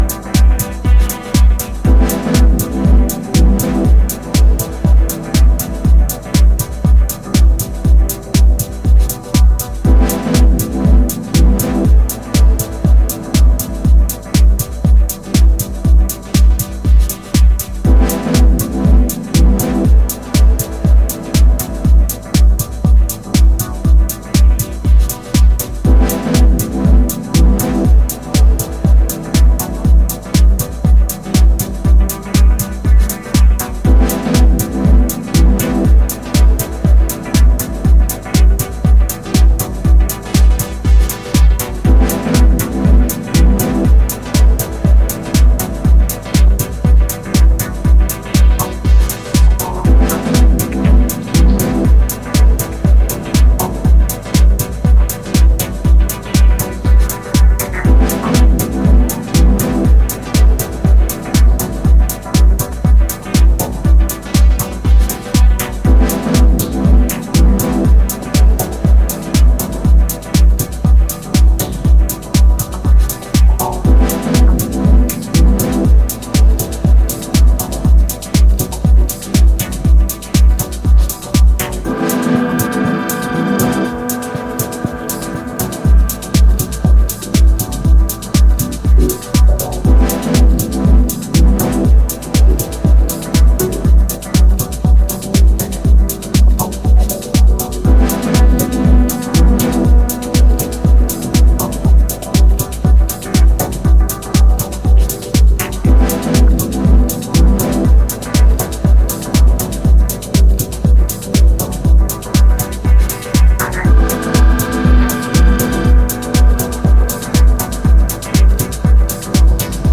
Here we have three super-deep yet driving cuts